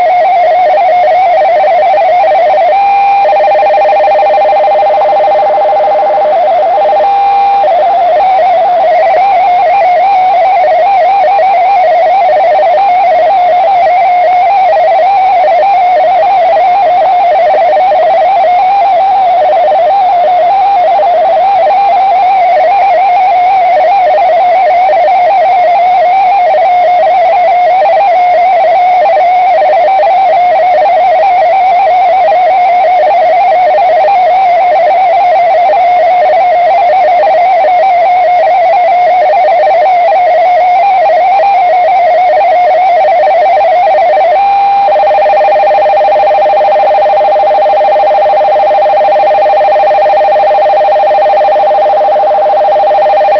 COQUELET-8 26.67 BAUD - ДЕКОДИРУЕТСЯ